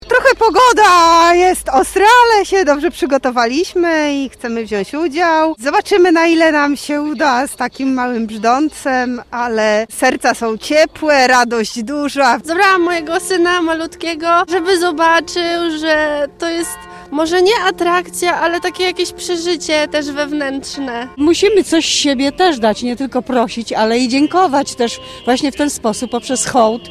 uczestnicy_pulawskiego_orszaku.mp3